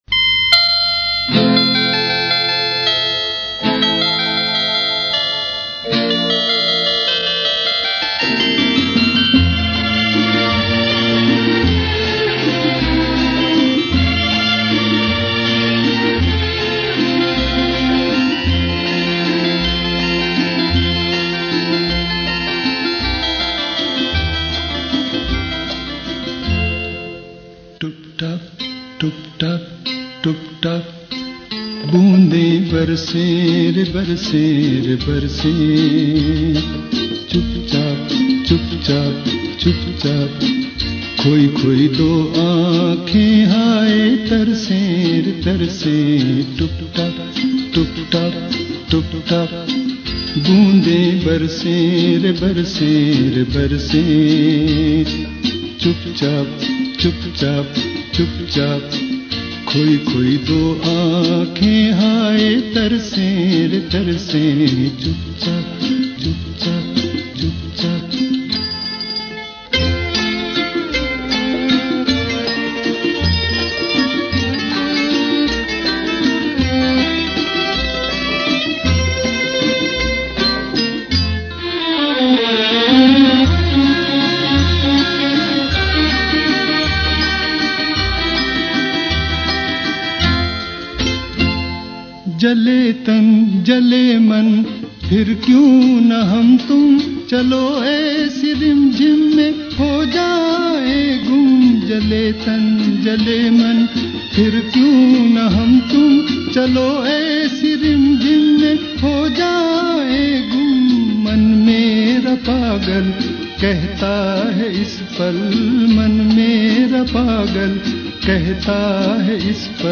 (Hindi)